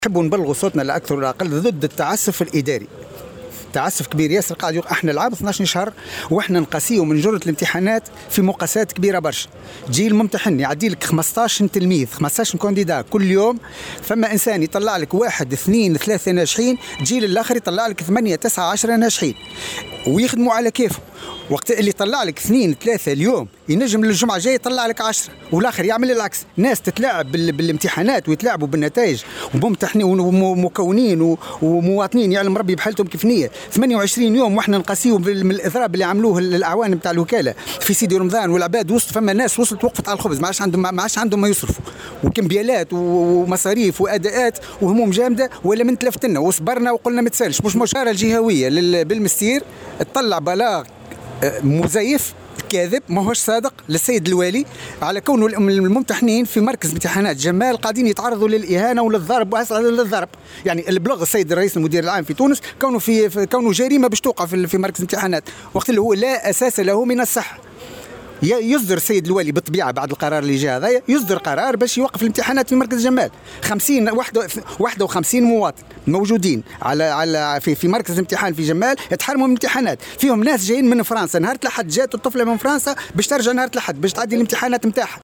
في تصريح لمراسل "الجوهرة أف أم"